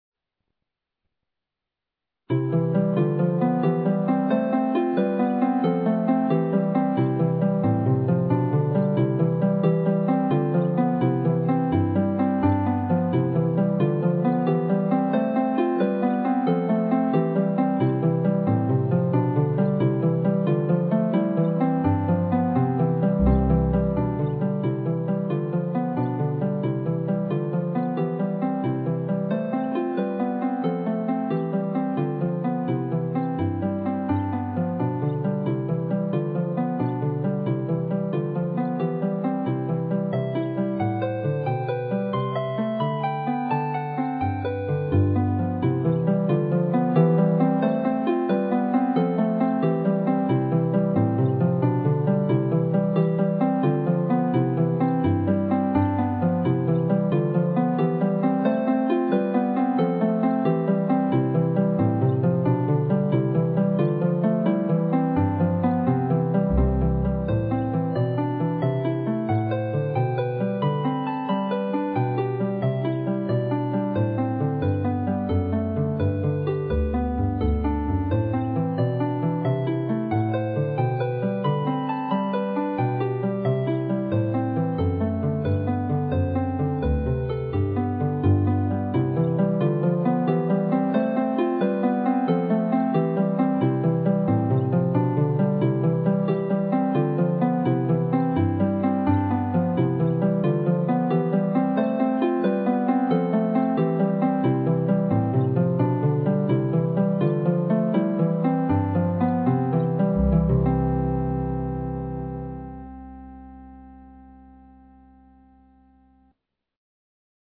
A ballade for (Celtic or pedal) harp, composed in 2011.